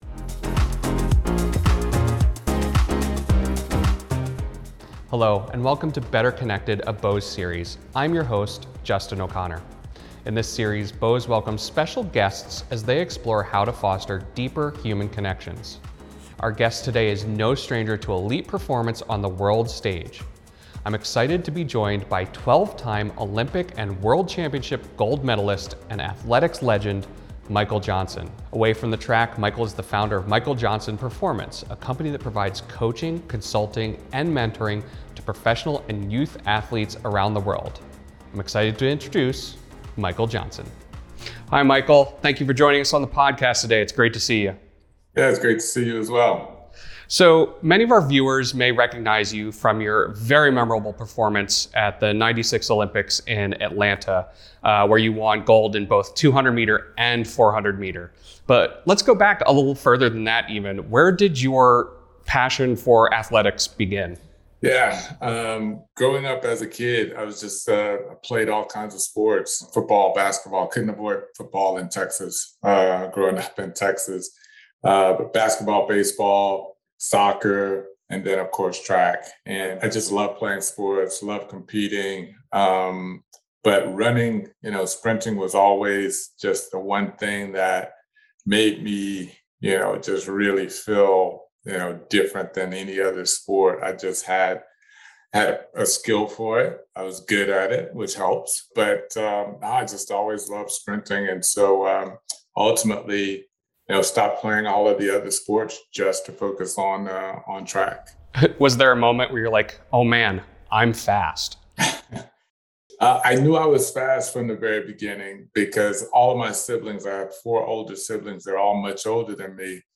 In this conversation about Staying Motivate, Michael discusses the impact the pandemic had on sports, how coaching adapted to virtual environments, and key takeaw…